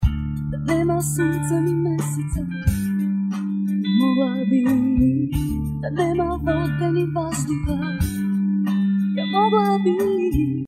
Noise reduction